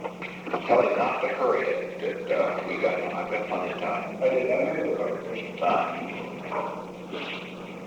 Secret White House Tapes
Location: Oval Office
The President met with Alexander P. Butterfield.